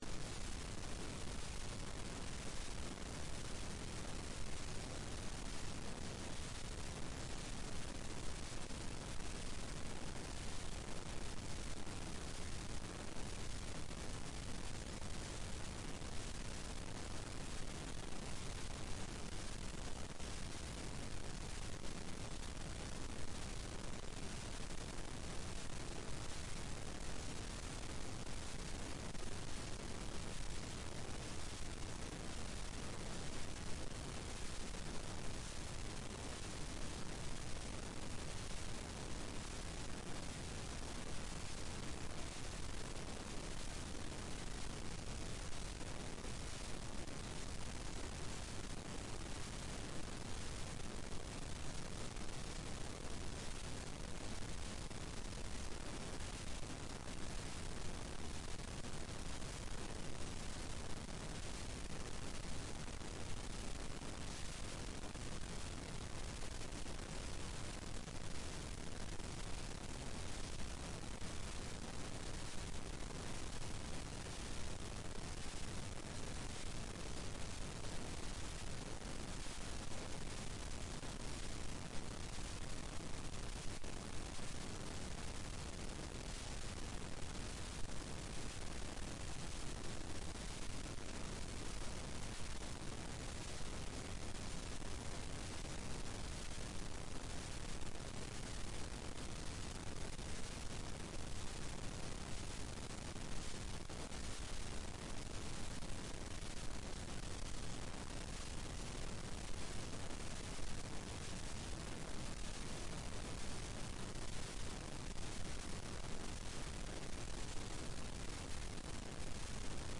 Rom: Store Eureka